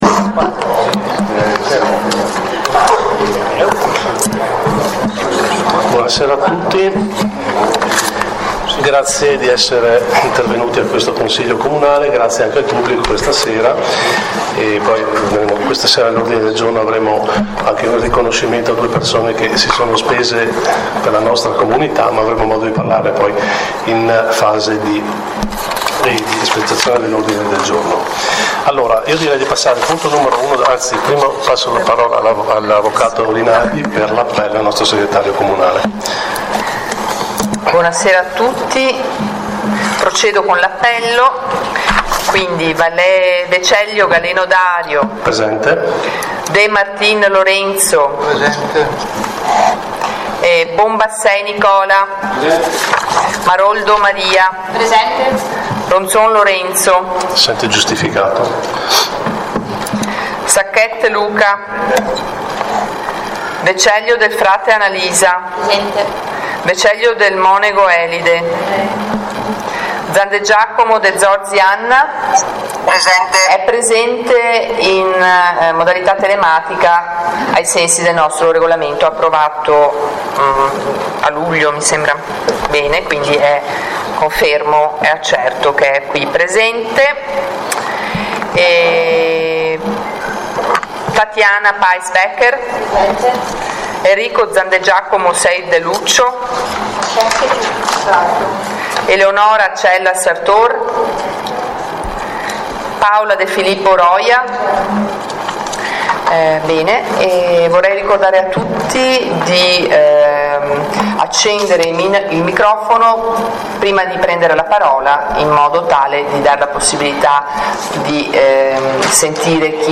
In questa pagina sono disponibili le registrazioni audio delle sedute del Consiglio Comunale del Comune di Auronzo di Cadore.